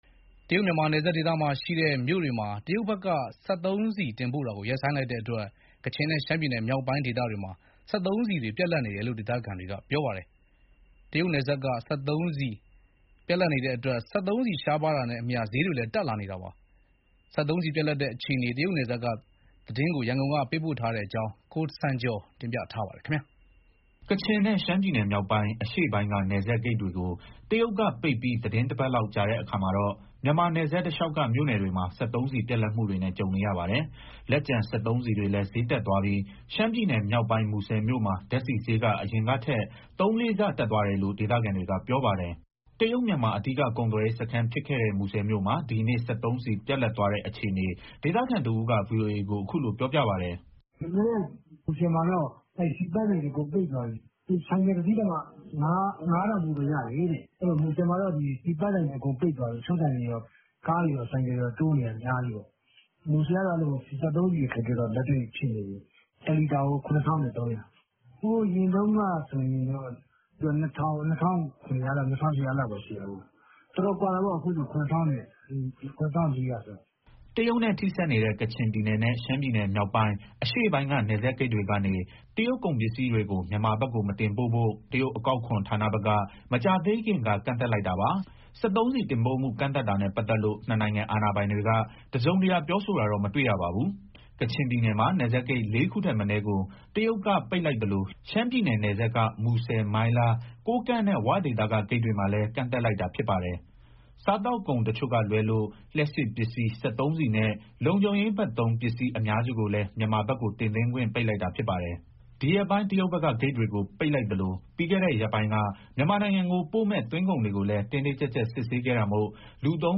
တရုတ်- မြန်မာနယ်စပ်ဒေသမှာရှိတဲ့ မြို့တွေကို စက်သုံးဆီ တင်ပို့တာကို တရုတ်ဘက်က ရပ်ဆိုင်းလိုက်တဲ့အတွက် ကချင်နဲ့ ရှမ်းပြည်နယ်မြောက်ပိုင်းမှာ စက်သုံးဆီတွေ ပြတ်လပ်နေတယ်လို့ ဒေသခံတွေက ပြောပါတယ်။ စက်သုံးဆီရှားပါးလာတာနဲ့အမျှ ဈေးတွေလည်း တက်လာနေတယ်လို့ ပြောပါတယ်။ တရုတ်ပြည်နယ်စပ်က စက်သုံးဆီပြတ်လပ်တဲ့ အခြေအနေ ရန်ကုန်ကနေ သတင်းပေးပို့ထားပါတယ်။
ကချင်နဲ့ ရှမ်းပြည်နယ်မြောက်ပိုင်း၊ အရှေ့ပိုင်းက နယ်စပ်ဂိတ်တွေကို တရုတ်ကပိတ်ပြီး သီတင်းတပတ်လောက် ကြာတဲ့အခါမှာတော့ မြန်မာနယ်စပ်တလျှောက်က မြို့နယ်တွေမှာ စက်သုံးဆီပြတ်လပ်မှုတွေနဲ့ ကြုံနေရပါတယ်။ လက်ကျန် စက်သုံးဆီတွေလည်း ဈေးတက်သွားပြီး ရှမ်းပြည်နယ်မြောက်ပိုင်း မူဆယ်မြို့မှာ ဓာတ်ဆီဈေးက အရင်ကထက် သုံးလေးဆ တက်သွားတယ်လို့ ဒေသခံတွေက ပြောပါတယ်။ တရုတ်-မြန်မာ အဓိက ကုန်သွယ်ရေးစခန်းဖြစ်ခဲ့တဲ့ မူဆယ်မြို့မှာ ဒီနေ့ စက်သုံးဆီပြတ်လပ်သွားတဲ့အခြေအနေ ဒေသခံတဦးက ဗွီအိုအေကို အခုလိုပြောပြပါတယ်။